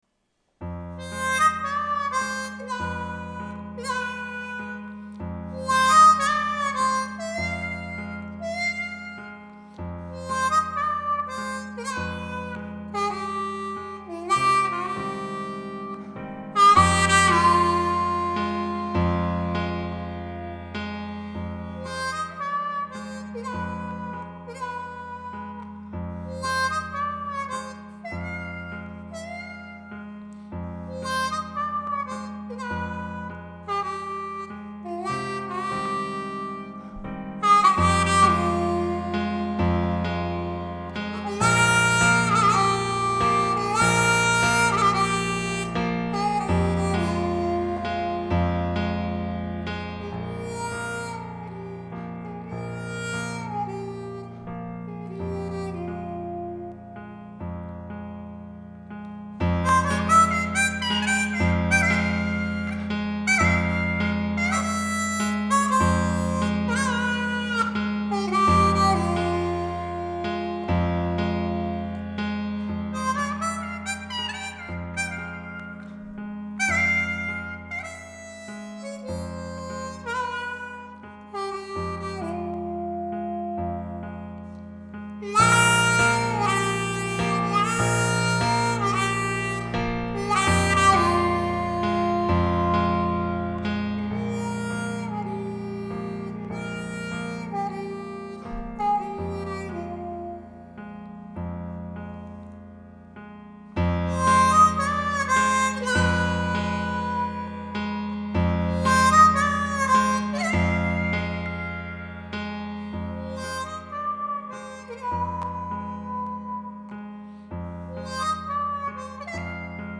harmonica
Diminishift test driving area
Some time ago I came up with a new tuning, and named it Diminishift.